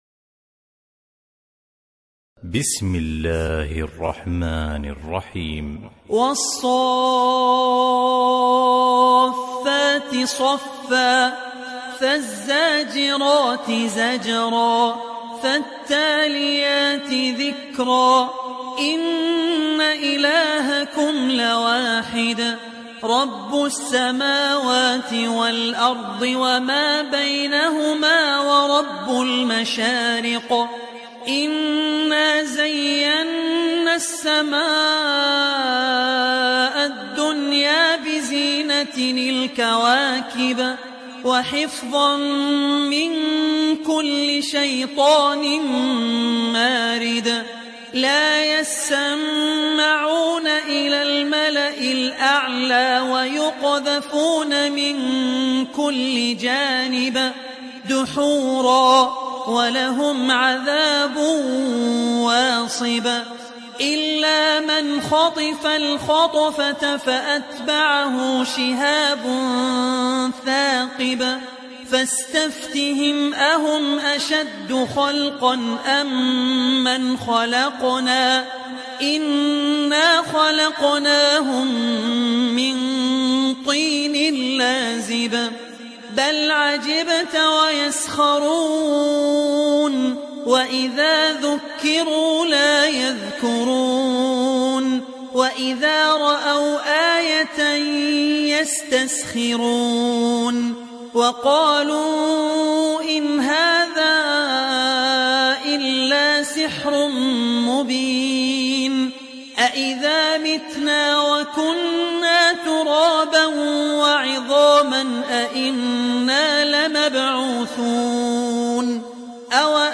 Sourate As Saffat Télécharger mp3 Abdul Rahman Al Ossi Riwayat Hafs an Assim, Téléchargez le Coran et écoutez les liens directs complets mp3